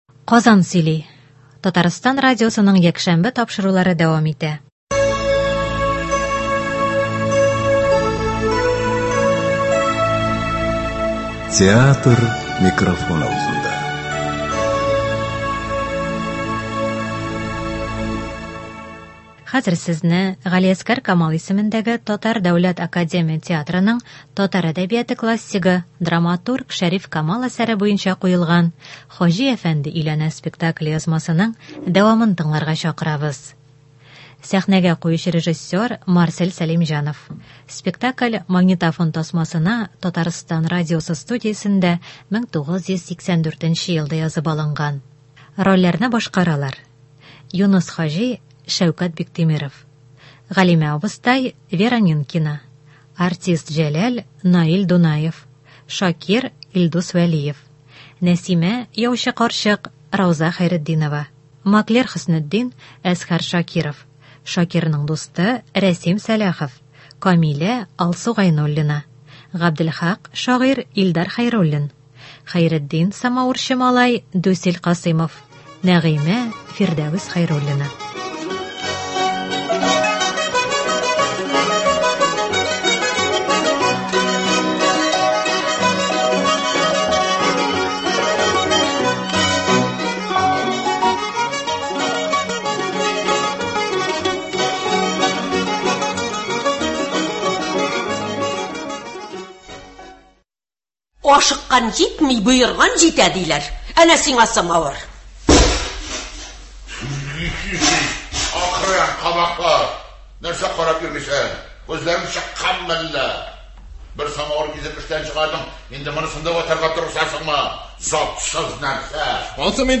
Спектакль магнитофон тасмасына Татарстан радиосы студиясендә 1984 елда язып алынган.
“Хаҗи әфәнде өйләнә”. Г.Камал ис.ТДАТ спектакленең радиоварианты. 1 өлеш.